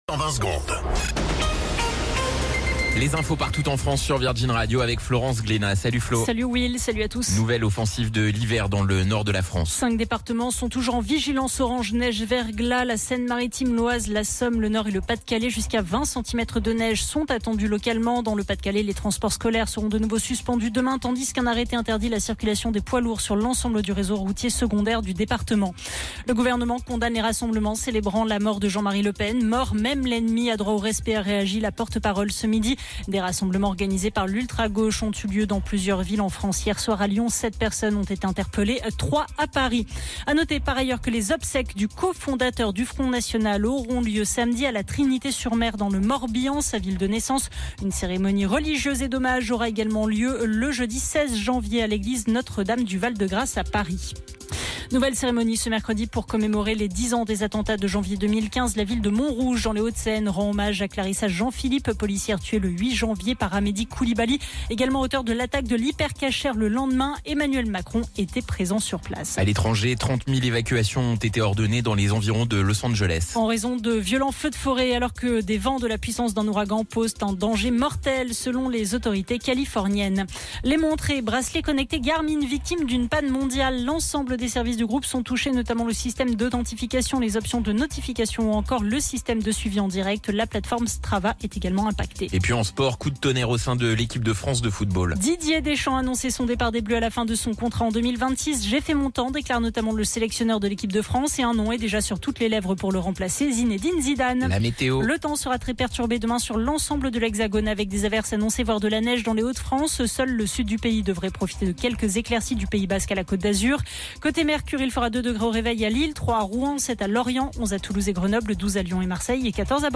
Flash Info National 08 Janvier 2025 Du 08/01/2025 à 17h10 .